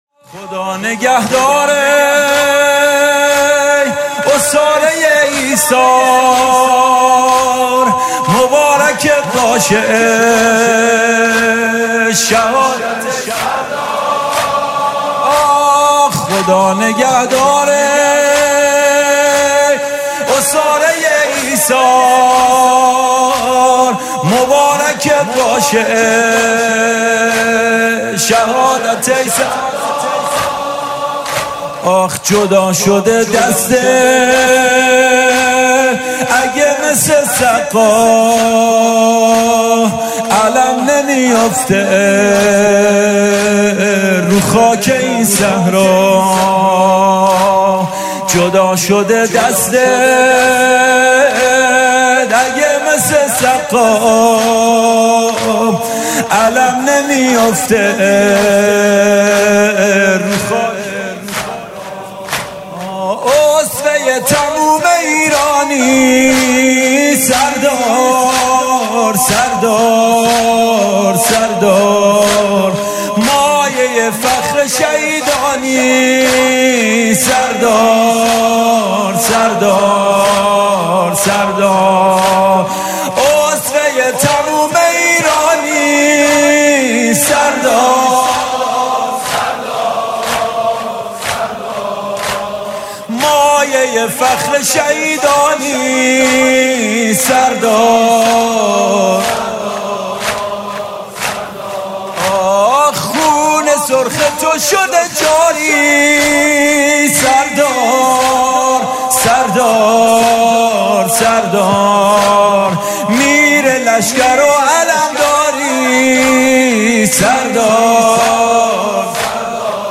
music-icon زمینه: مبارکت باشه شهادت ای سردار